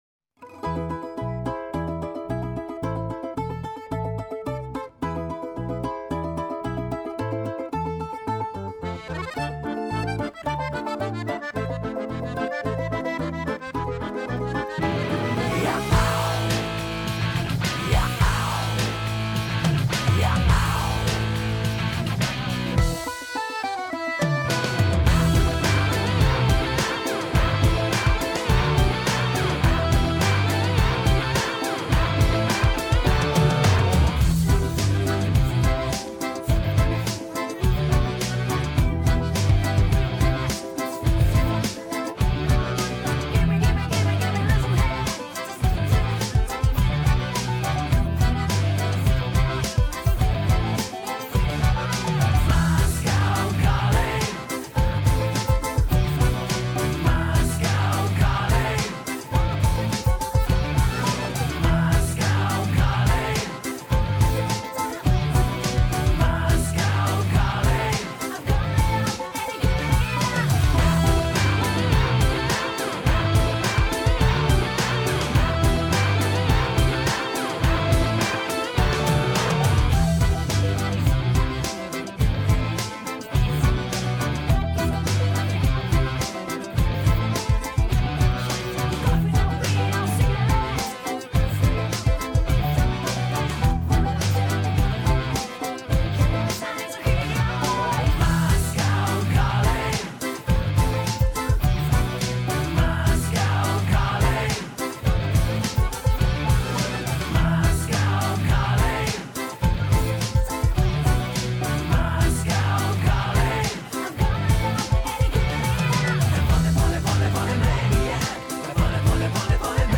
минусовка версия 53158